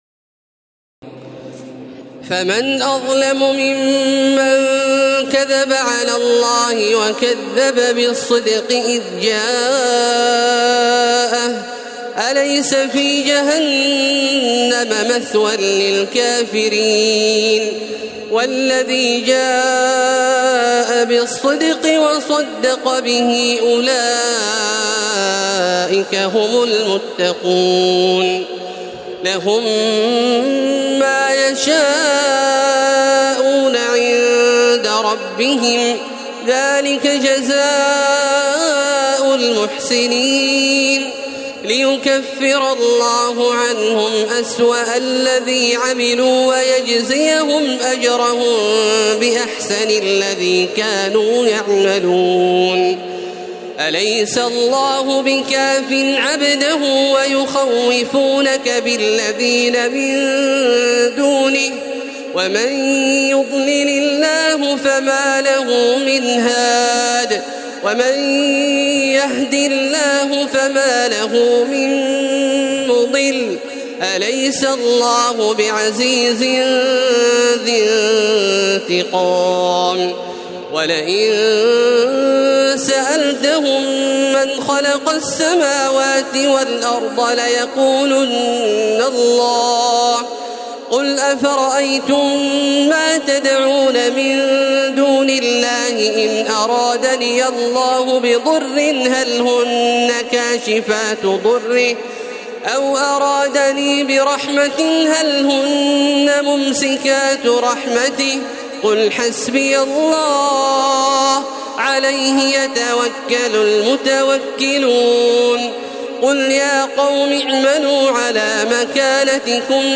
تراويح ليلة 23 رمضان 1432هـ من سور الزمر (32-75) و غافر (1-46) Taraweeh 23 st night Ramadan 1432H from Surah Az-Zumar and Ghaafir > تراويح الحرم المكي عام 1432 🕋 > التراويح - تلاوات الحرمين